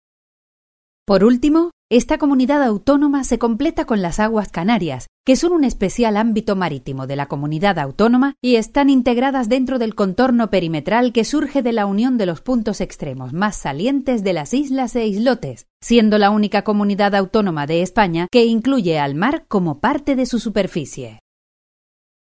Banco de voces de locutores con acentos regionales de Mallorca, Canarias y de Andalucía
Locutoras de Canarias. Locutores de Canarias. Locutores canarios